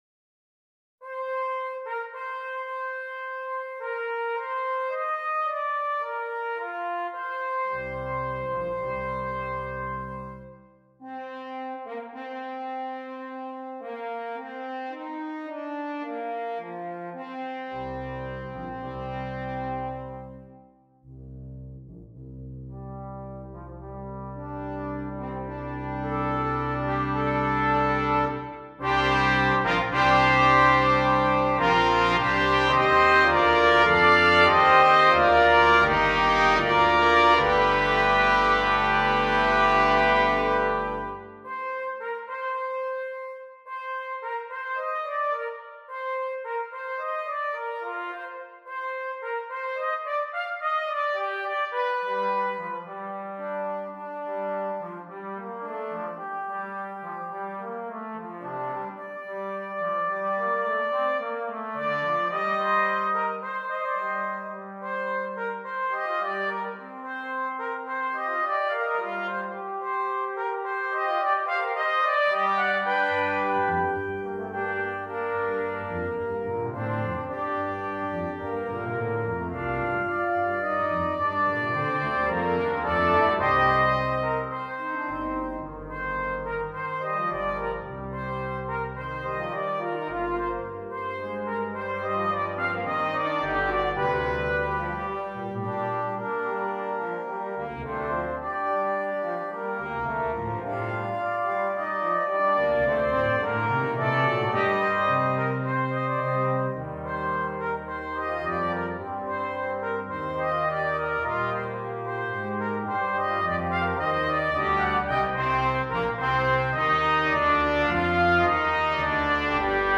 Brass Quintet
Intended to evoke a medieval feel